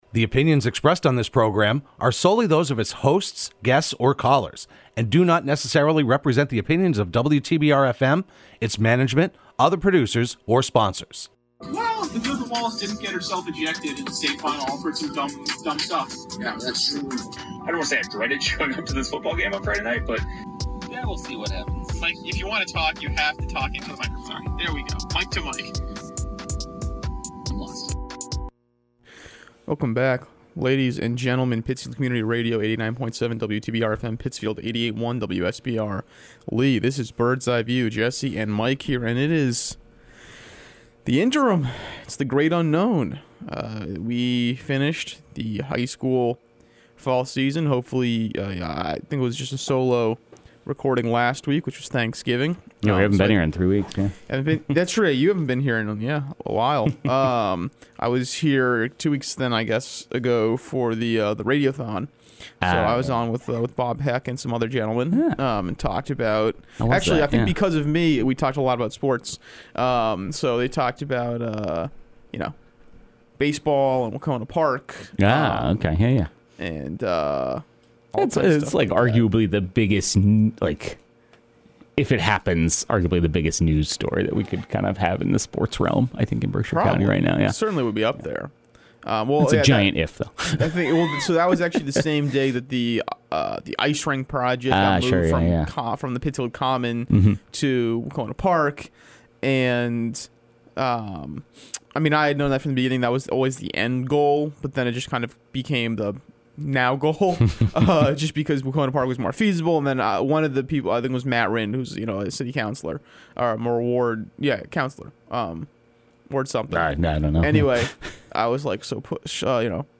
Broadcast live every Thursday morning at 10am on WTBR.